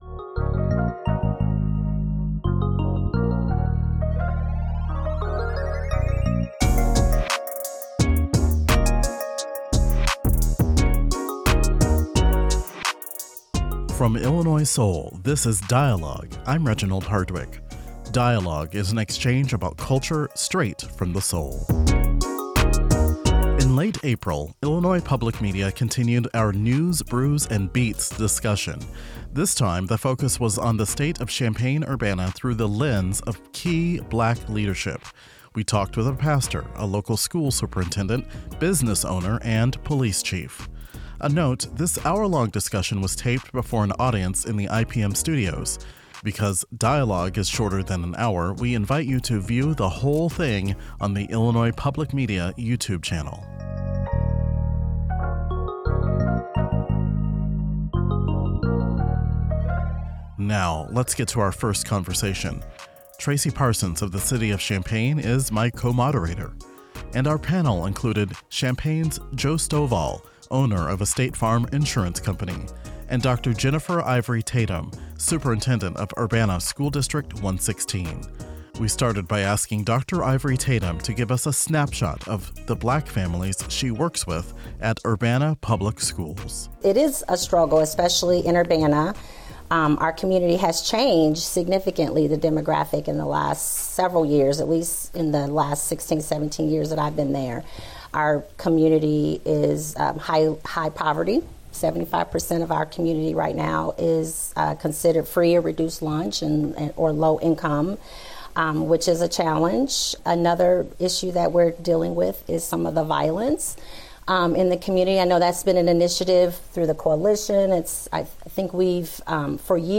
In this segment of Dialogue, hear from local Black leaders about the issues, changes and conversations shaping Champaign-Urbana's Black community.